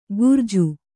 ♪ gurju